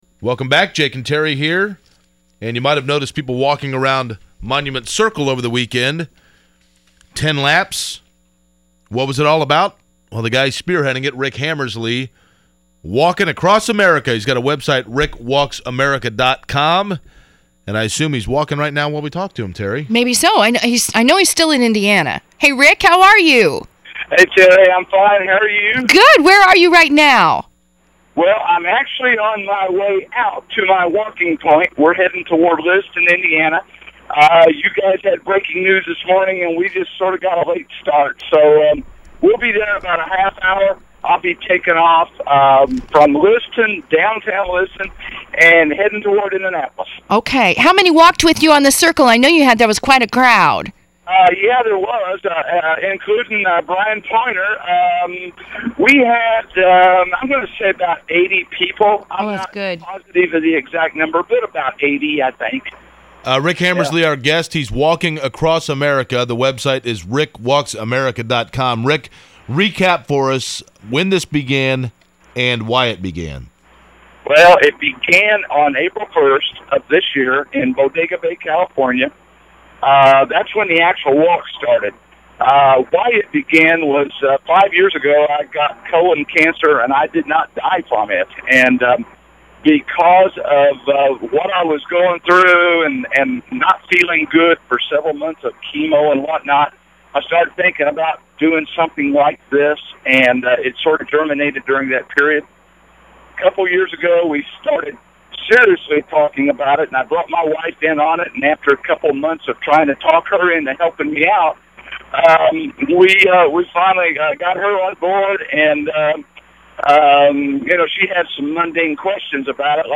Media Interviews